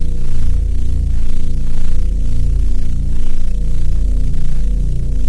sol_reklam_link sag_reklam_link Warrock Oyun Dosyalar� Ana Sayfa > Sound > Ambient > machines Dosya Ad� Boyutu Son D�zenleme ..
WR_generator.wav